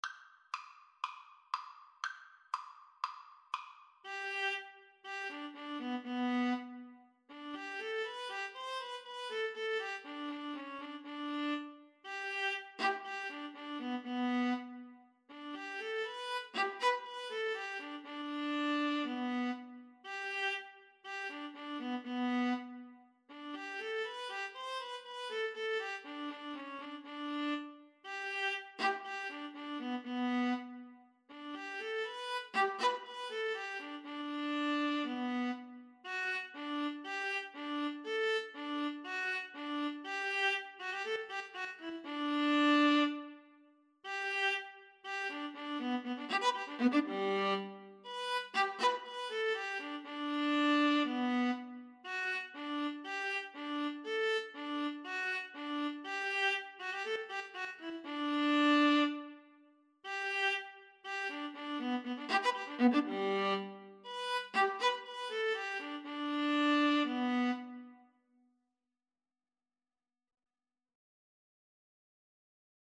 originally written for horns.
4/4 (View more 4/4 Music)
Classical (View more Classical Violin-Viola Duet Music)